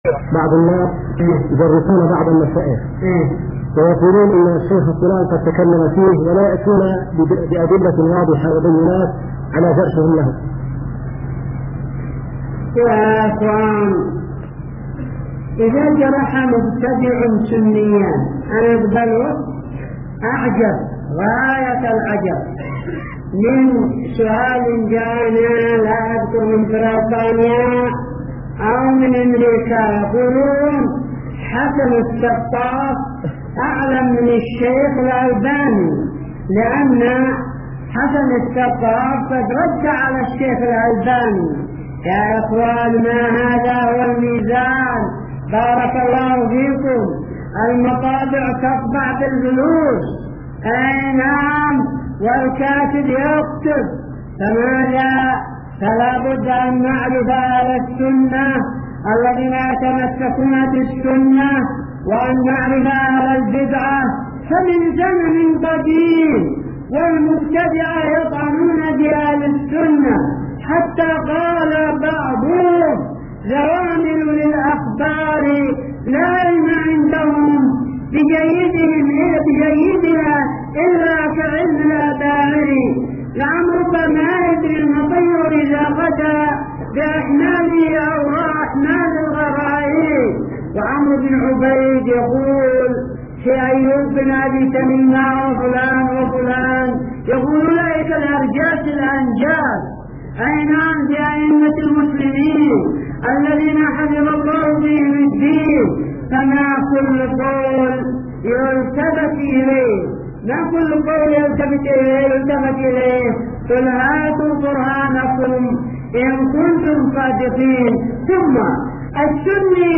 تحميل 0.83MB Tweet نص الإجابة: من شريط : ( محاضرة وأسئلة هاتفية من إيرلندا )